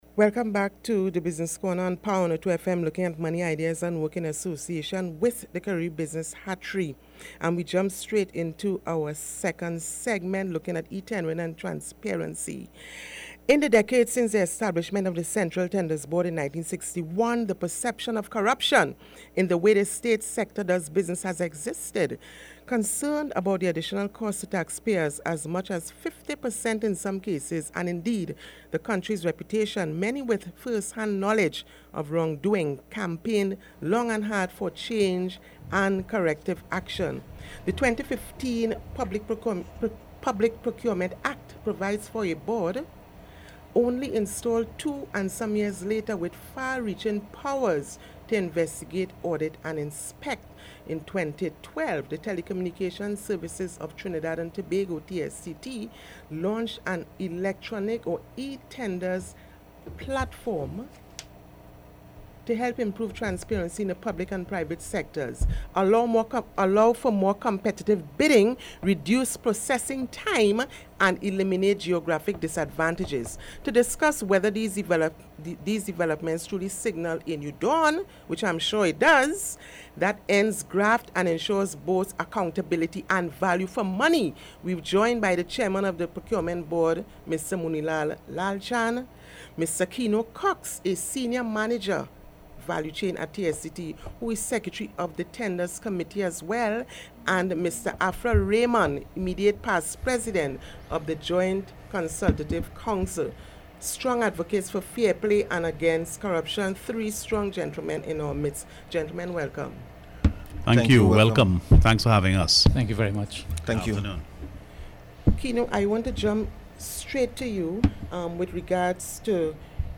AUDIO: Interview on Business corner on Power 102 FM – 5 March 2018